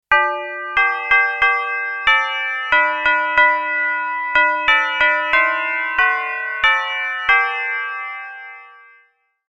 Christmas-doorbell-chime-sound-effect.mp3